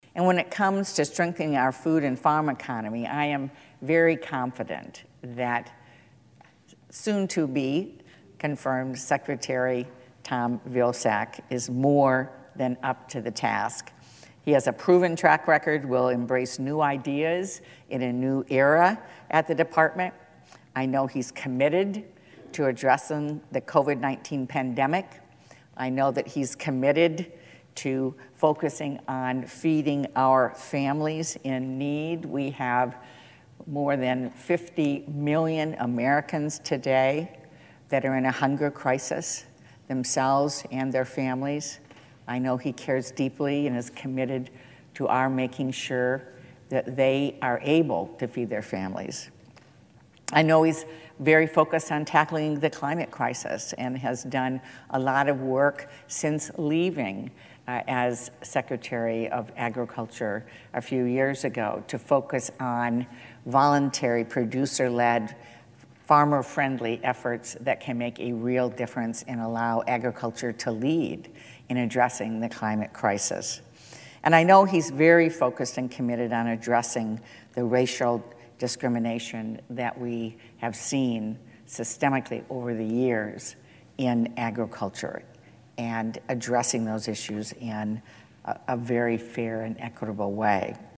Senate Agriculture Committee Chairwoman Debbie Stabenow (D-Michigan) stood and spoke in support of Vilsack for Secretary. She talked about the problems that USDA will be able to help during this pandemic.